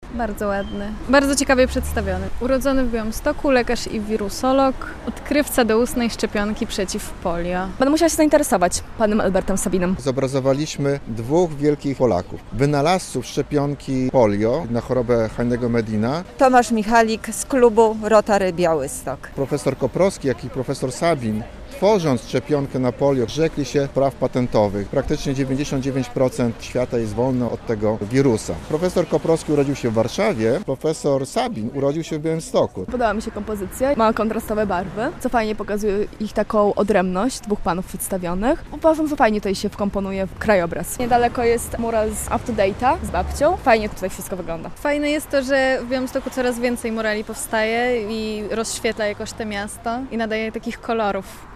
Wizerunki twórców szczepionki przeciwko polio na nowym muralu w Białymstoku - relacja